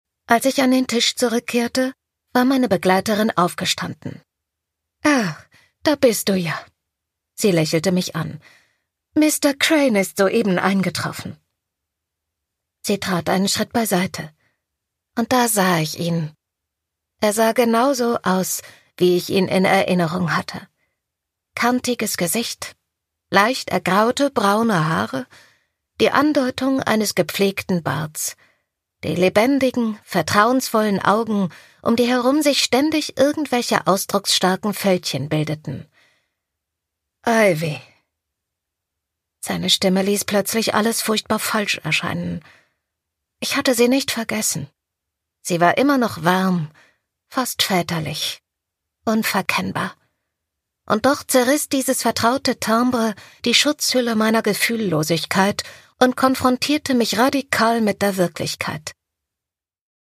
Produkttyp: Hörbuch-Download
Man spürt zu jeder Sekunde, dass sie diesen poetischen Roman und seine sinnliche Sprache liebt.